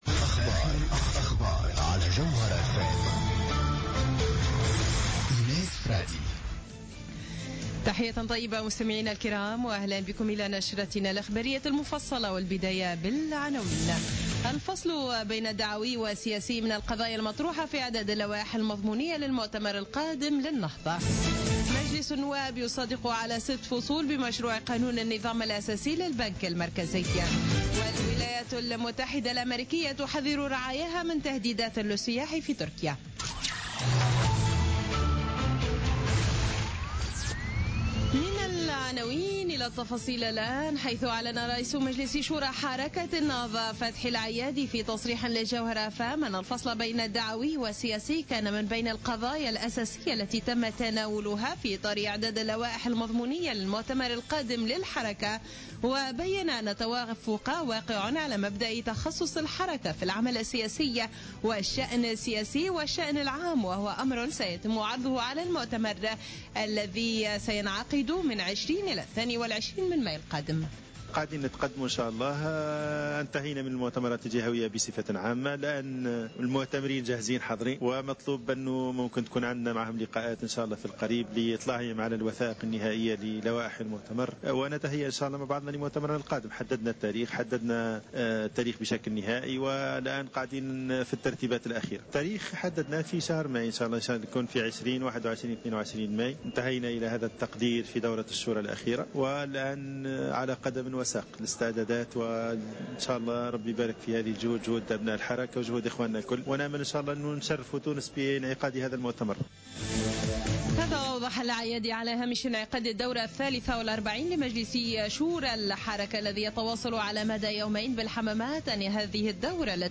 نشرة أخبار السابعة مساء ليوم السبت 9 أفريل 2016